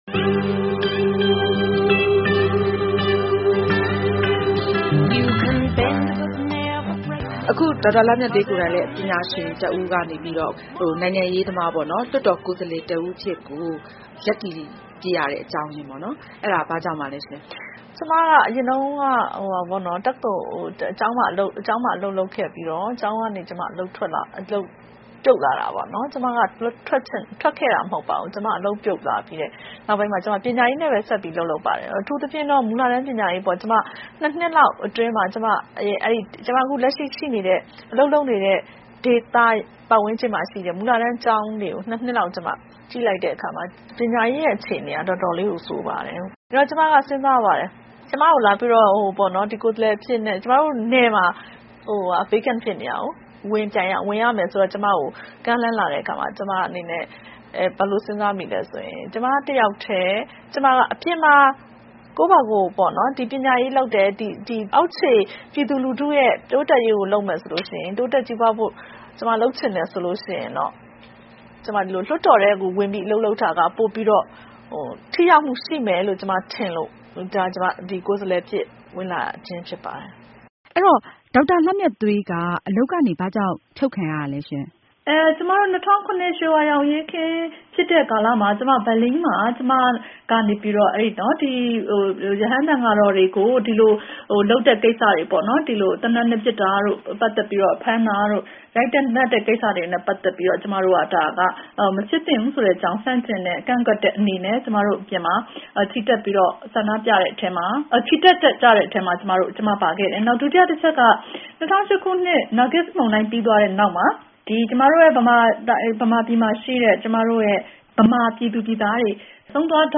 အမျိုးသမီးလွှတ်တော်အမတ် ဒေါက်တာလှမြတ်သွေး နဲ့ ဗွီအိုအေမေးမြန်းခန်း